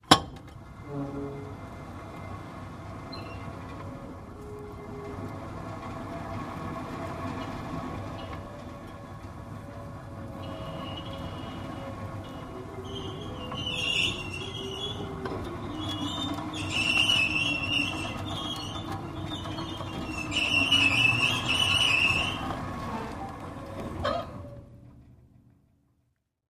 Stage Curtain: Pulley Squeaks.